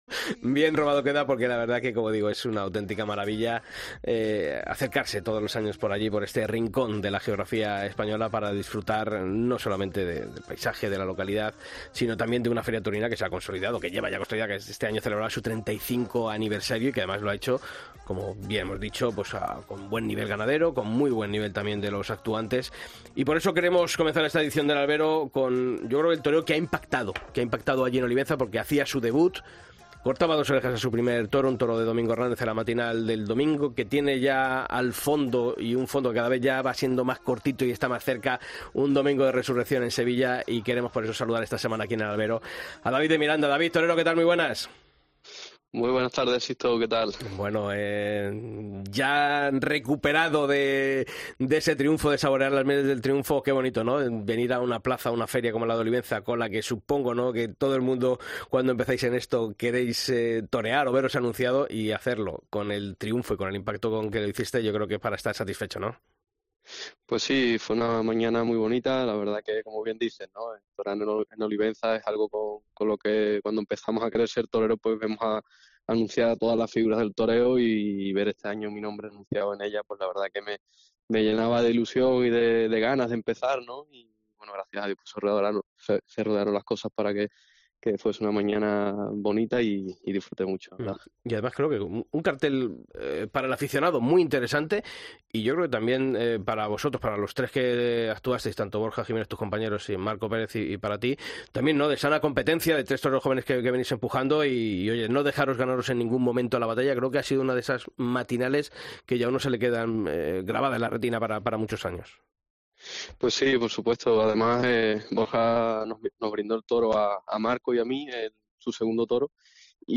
El torero onubense repasa en COPE su triunfo en Olivenza y explica cómo afronta una temporada clave en su carrera en la que estará en Valencia, Sevilla y Madrid.